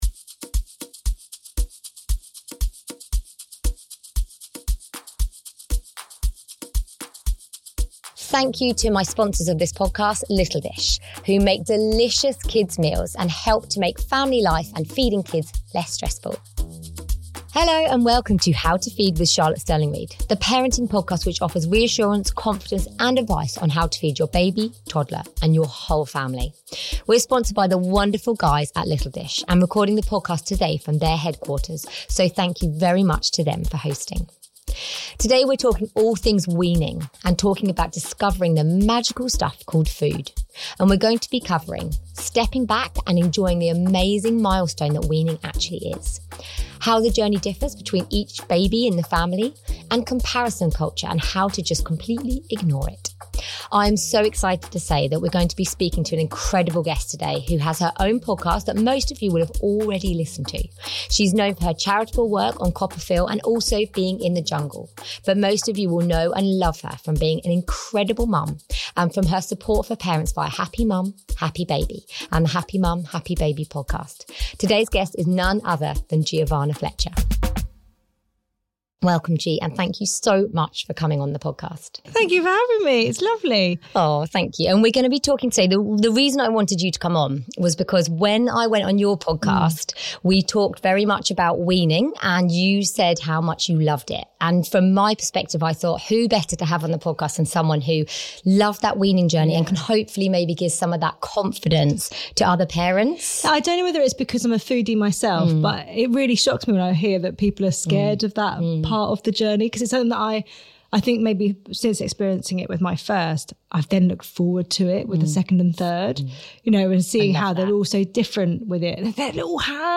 This Podcast is sponsored by Little Dish and recorded at their HQ.